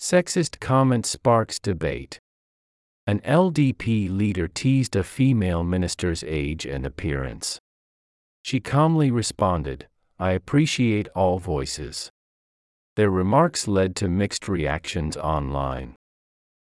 【速度：ややスロー】↑